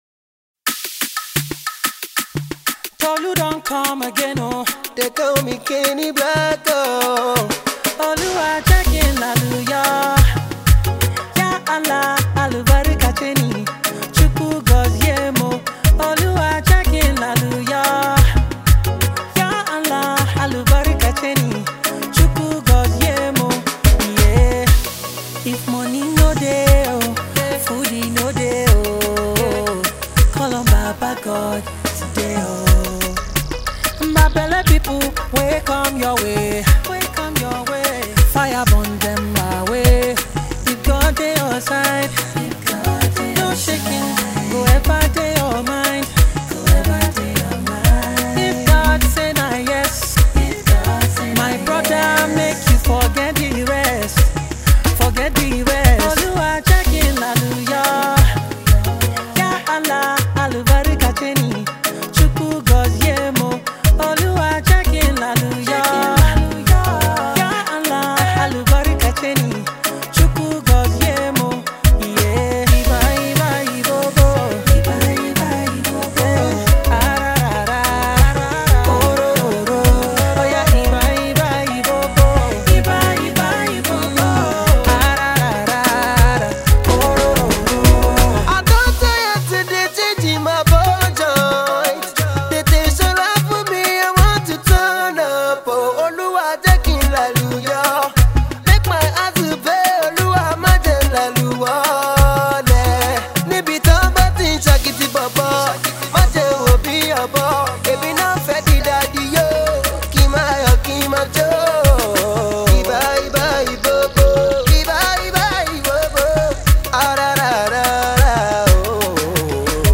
melodious sing-along song